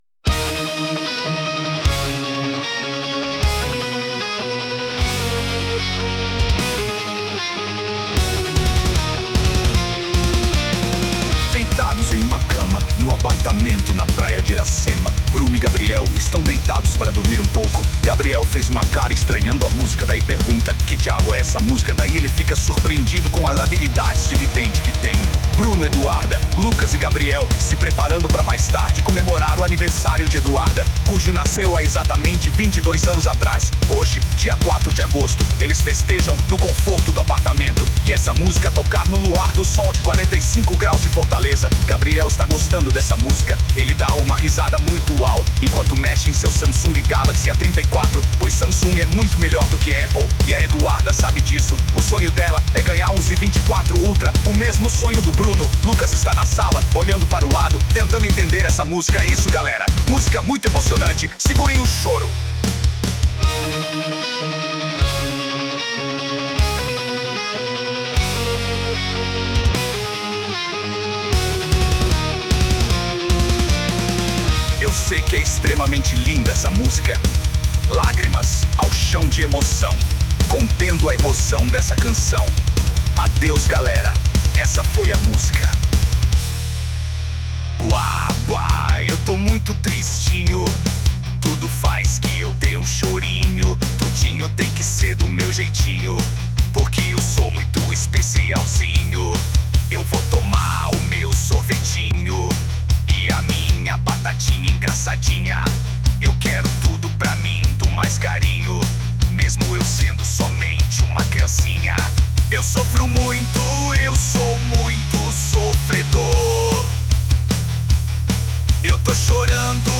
Versão Metal 2